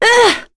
Lewsia_B-Vox_Damage_05.wav